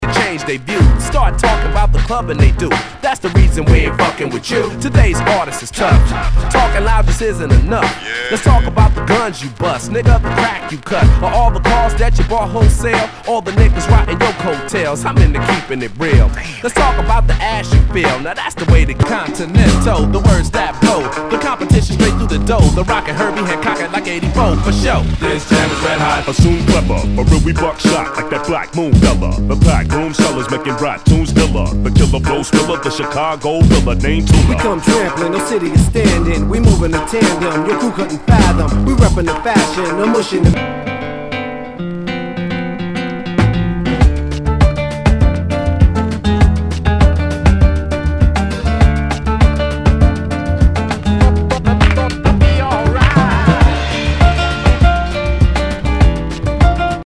HIPHOP/R&B
盤に傷多数あり全体に大きくチリノイズが入ります。